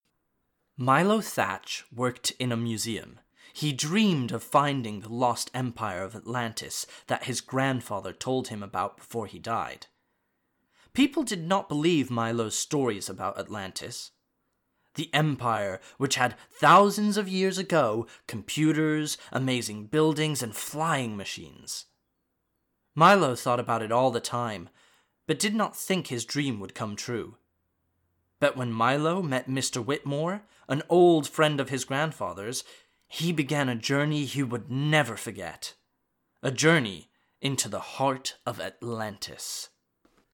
20s-30s. Male. US.
Commercials
Narration
Home Studio Read
• Rode NT1-A
• Neewer NW-5 vocal booth